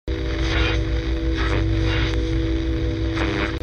جلوه های صوتی
دانلود آهنگ رادیو 24 از افکت صوتی اشیاء